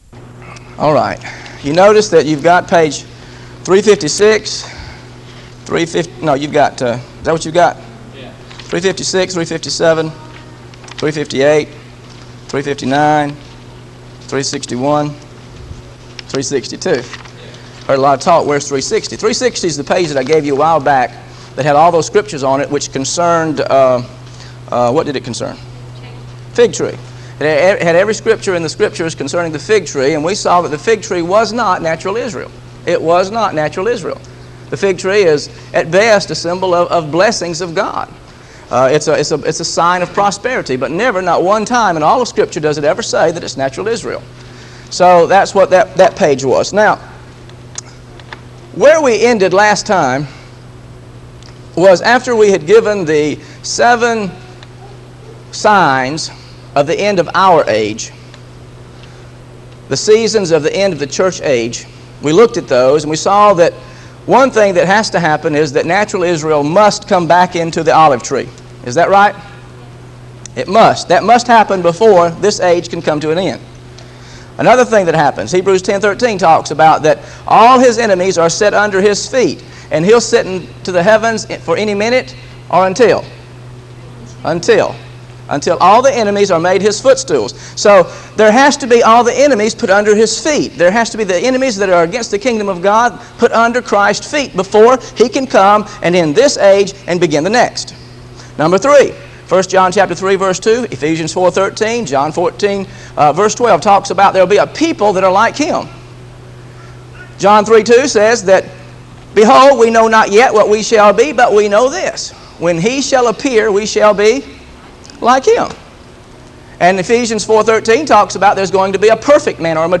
GOSPEL OF MATTHEW BIBLE STUDY SERIES This study of Matthew: Matthew 24 Verses 36-50 – As the Days of Noah Were is part of a verse-by-verse teaching series through the Gospel of Matthew.